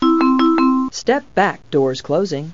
Almost Every 7k Announcement